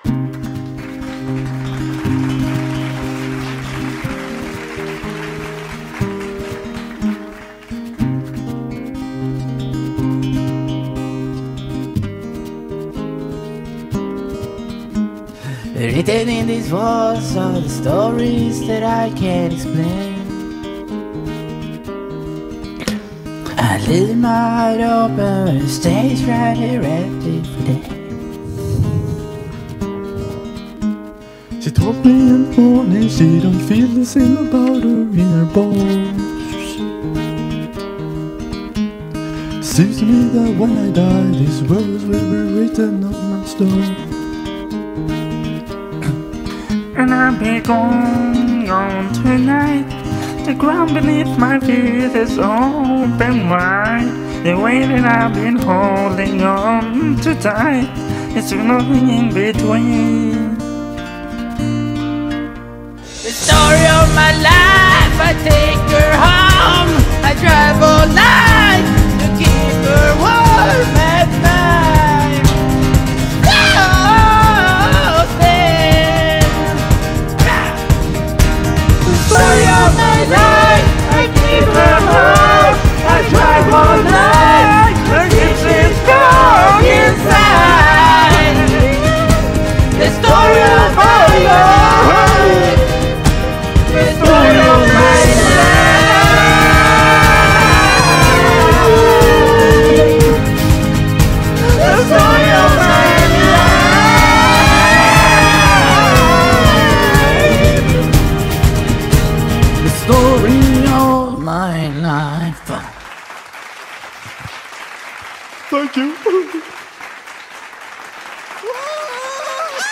BPM121
Audio QualityMusic Cut
replaced them with off-key vocals for comedic purposes